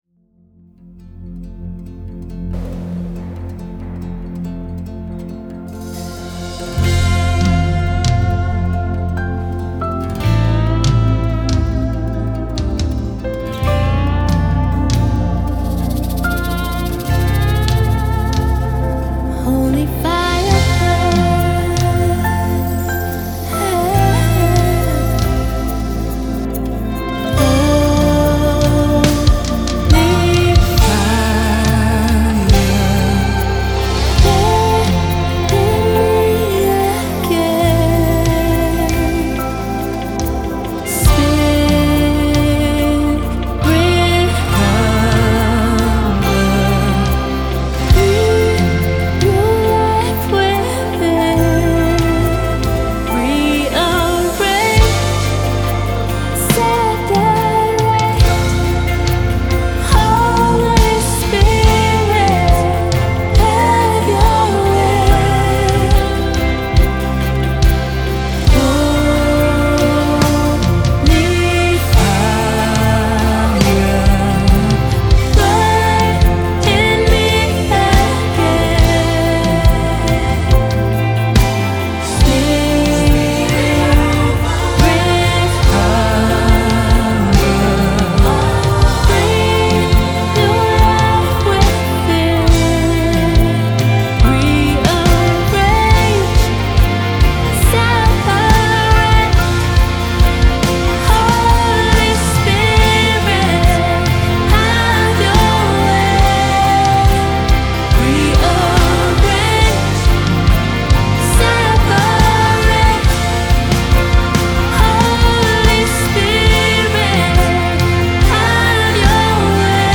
a passionate new sound